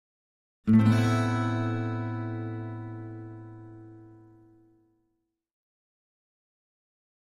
Acoustic Guitar - Minor Chord 4 - Orotund Septa Chord (7)